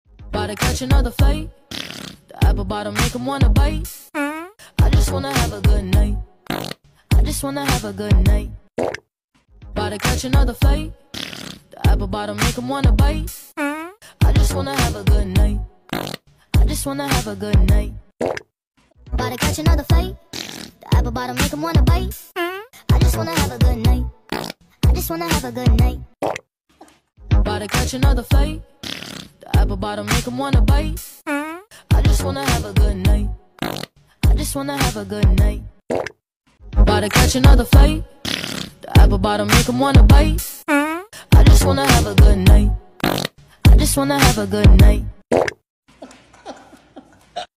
funy fart trend on tiktok sound effects free download